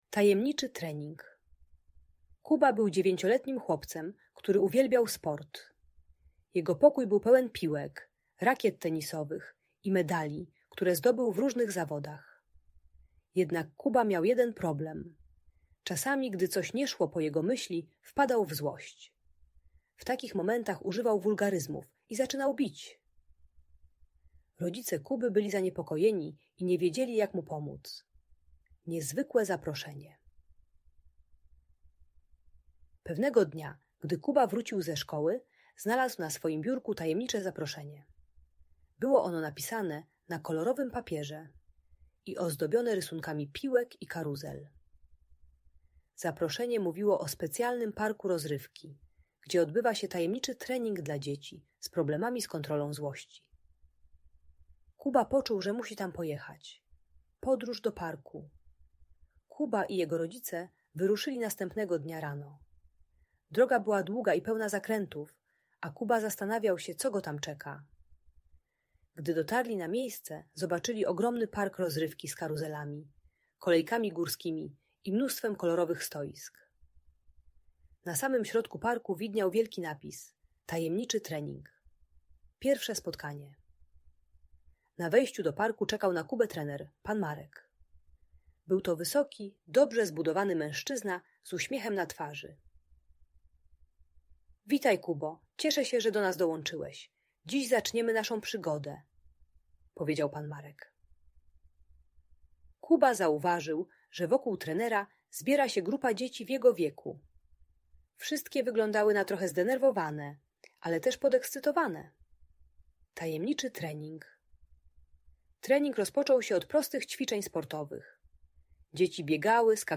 Tajemniczy Trening - Audiobajka